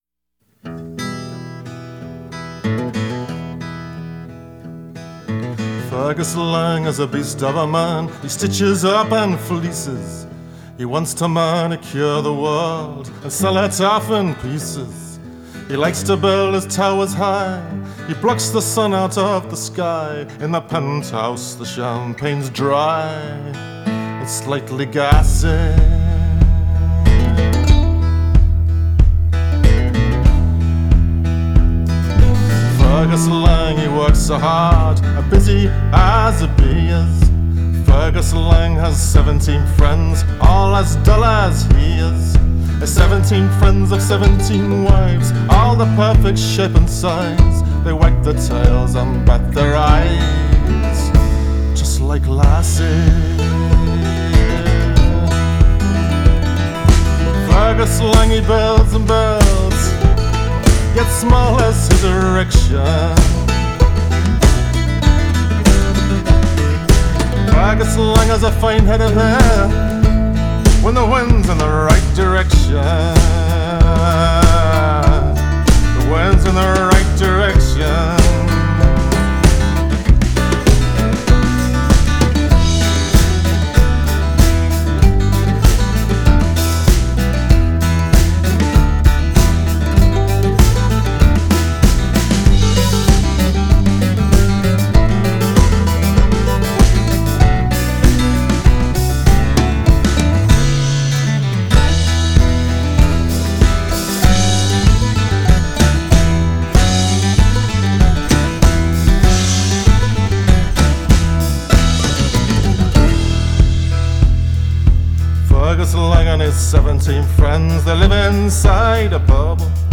the playing is solid and unadorned.